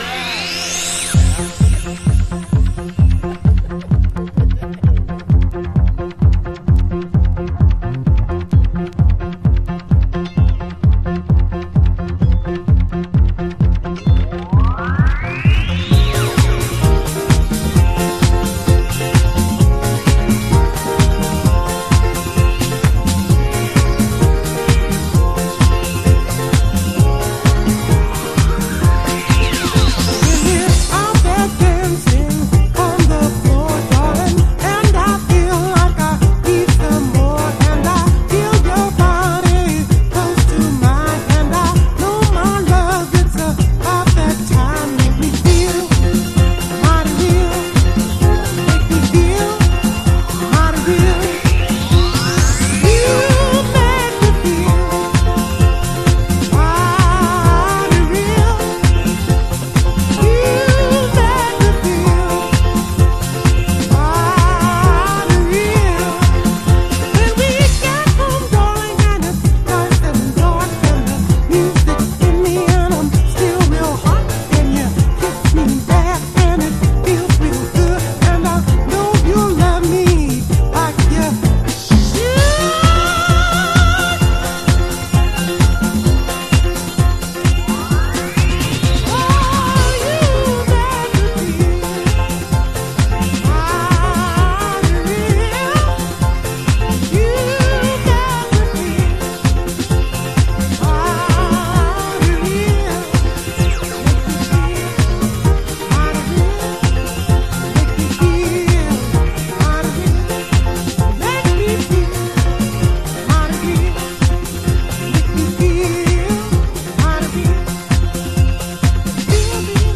# DISCO